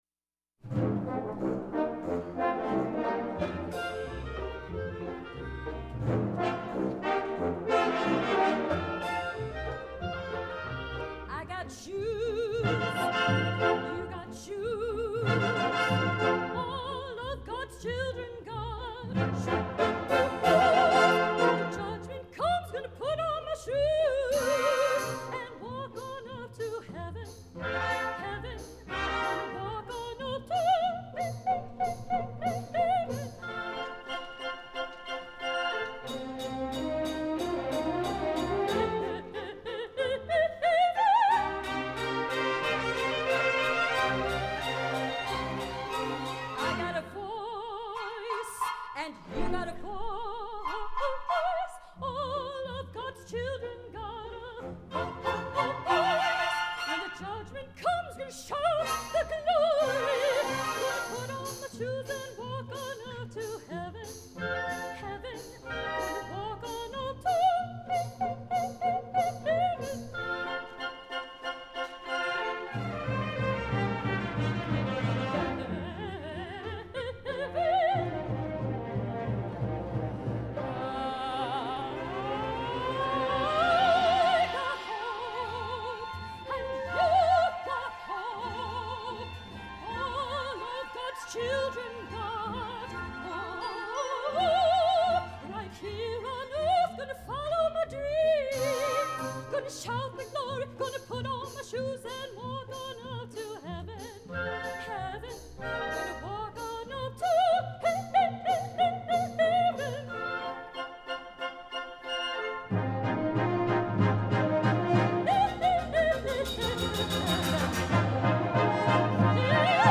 for Soprano and Orchestra (2010)